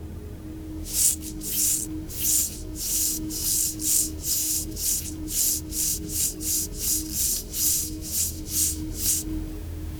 generate-soft-soothing-as-sr4velar.wav